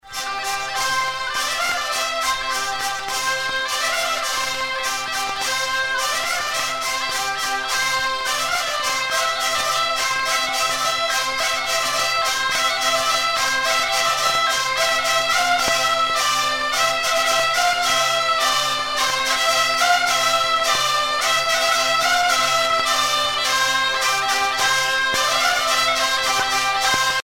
Usage d'après l'analyste gestuel : danse ;
Genre brève
Catégorie Pièce musicale éditée